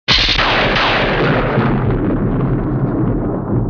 和大家分享一下暴雨素材
连环炸雷.wav